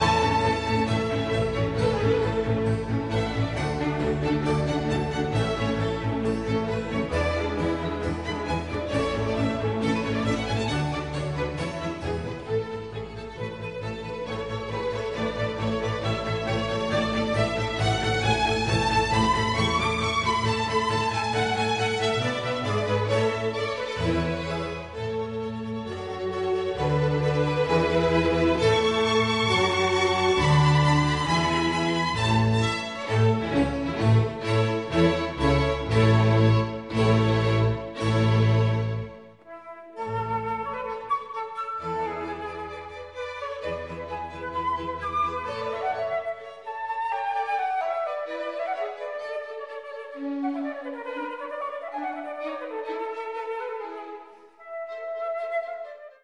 Flute concerto in D Major (I. Allegro, rec. Slovak Chamber Orchestra, CD Vivaldi-Stamic: Flute Concertos, Selected Sound Carrier 1995)